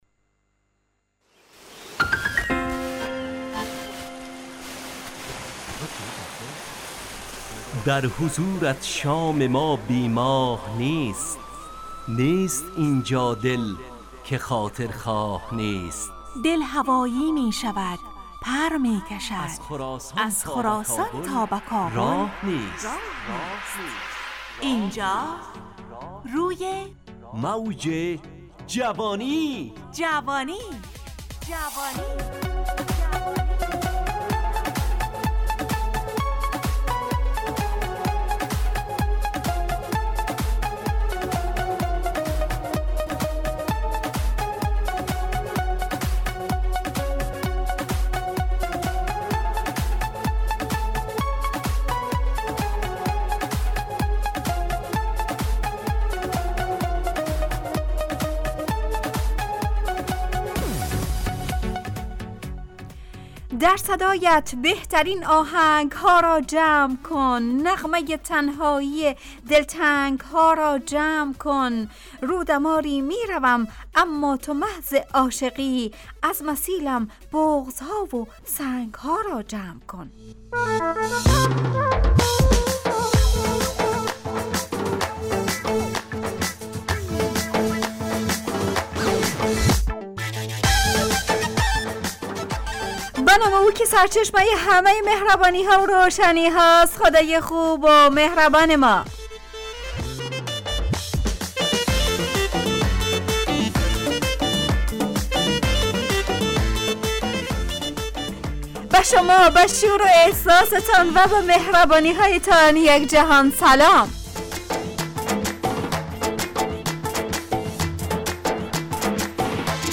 روی موج جوانی، برنامه شادو عصرانه رادیودری.
همراه با ترانه و موسیقی مدت برنامه 55 دقیقه .